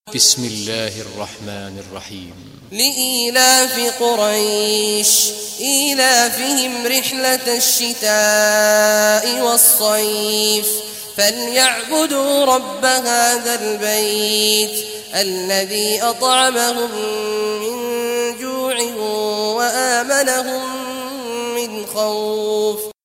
Surah Quraysh Recitation by Sheikh Abdullah Juhany
Surah Quraysh, listen or play online mp3 tilawat / recitation in Arabic in the beautiful voice of Sheikh Abdullah Awad al Juhany.